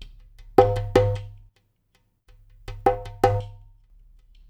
EPH DUMBEK.wav